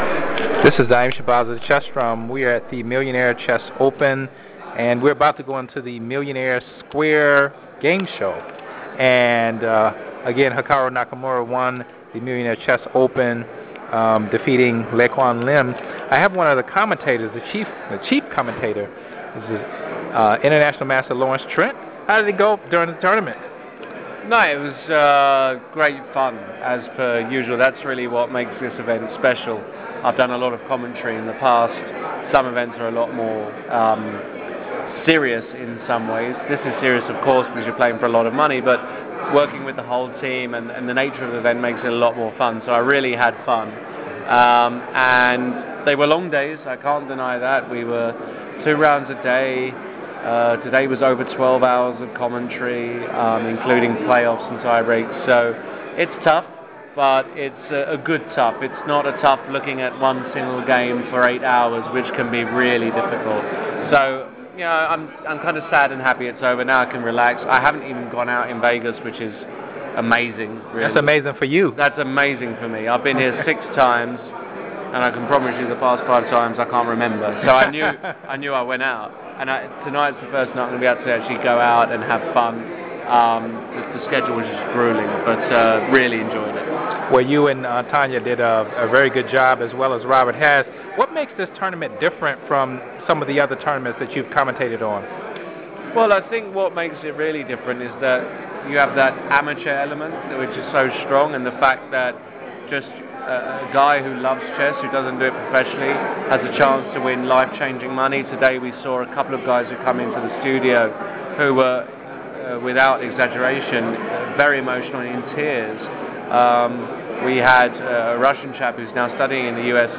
Drum Interviews @ ’15 Millionaire Chess